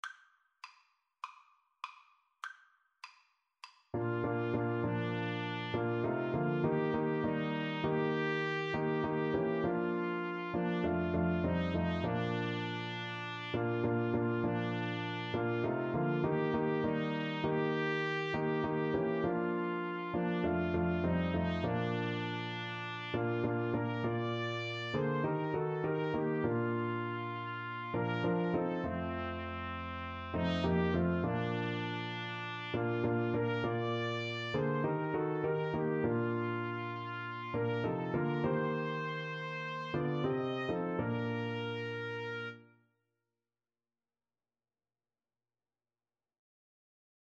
(with piano)